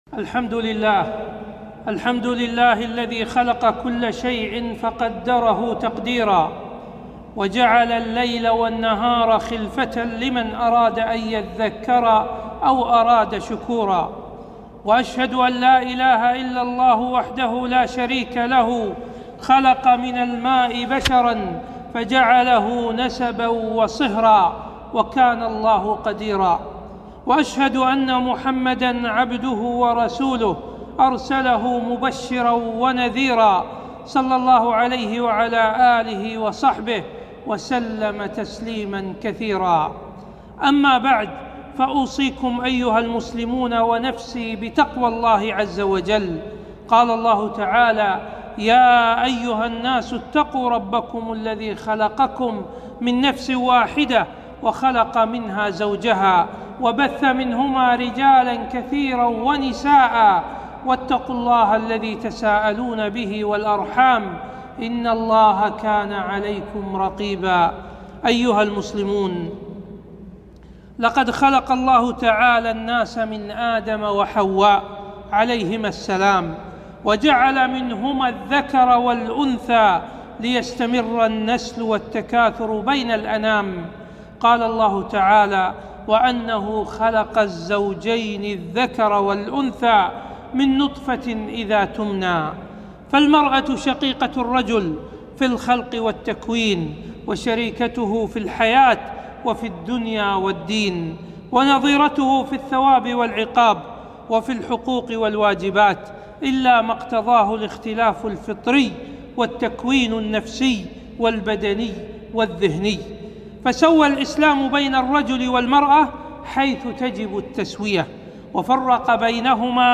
خطبة - واجبات المرأة المسلمة